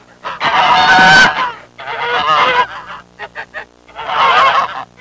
Bunch Of Geese Sound Effect
Download a high-quality bunch of geese sound effect.
bunch-of-geese.mp3